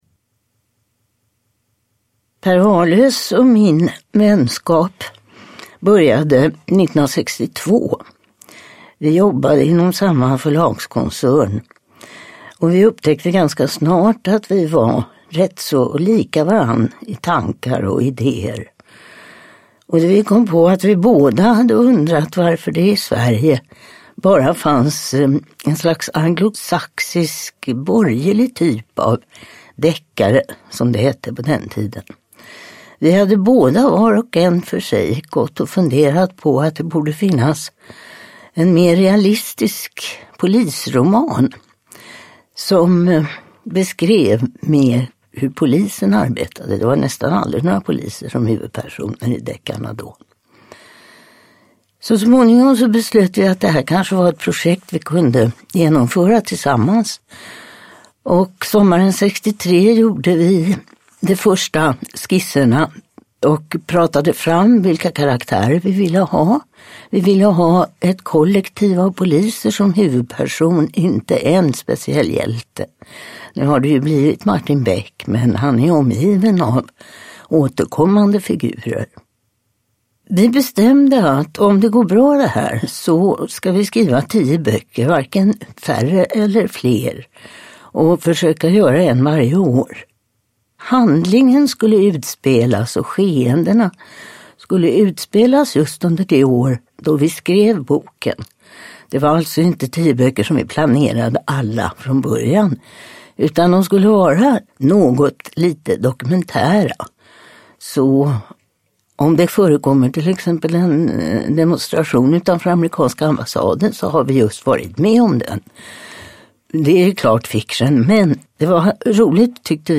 Roseanna – Ljudbok
Uppläsare: Torsten Wahlund